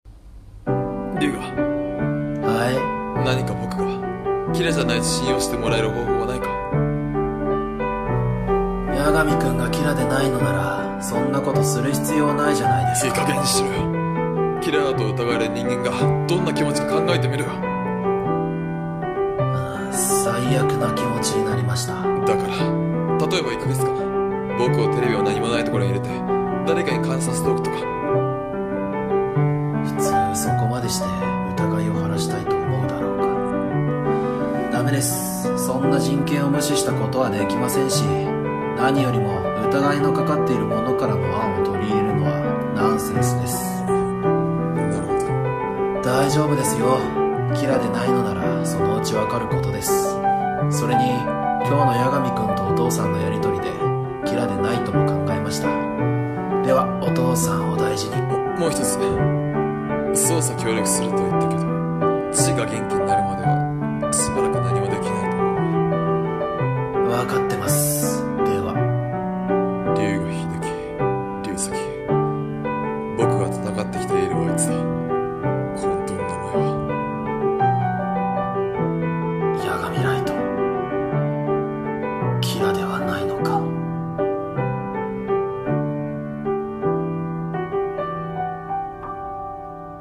DEATHNOTE声劇「見舞いの直後」